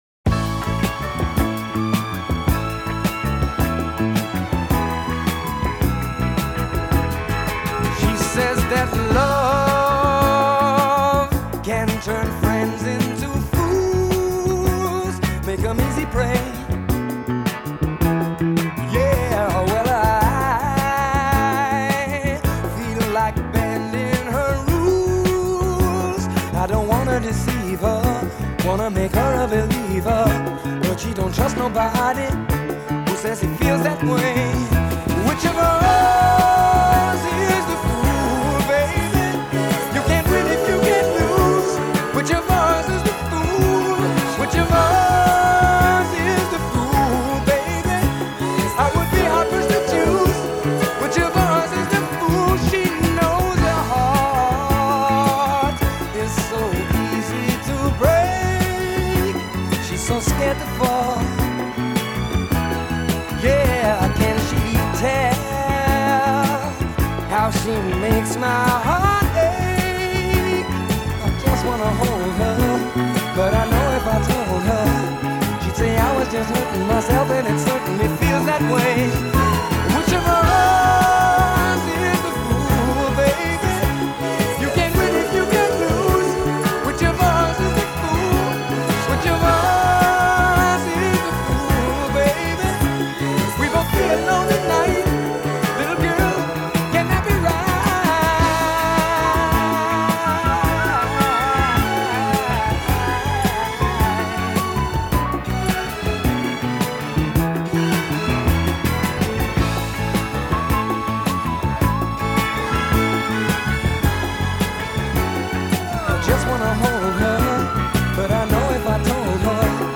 the return to the smooth sound sounds out of place.
his indelible bass line leading the way
even the strings here don’t sound schmaltzy.